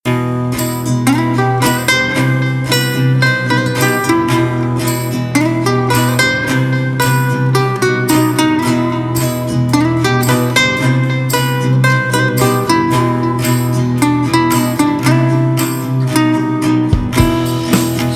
Домашняя студия и записи (гитара)
Пишу на айфон.